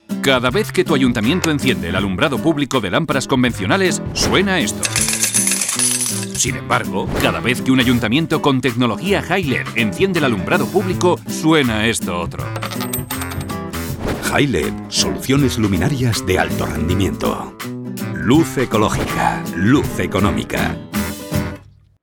Cuña Publicitaria Radio 1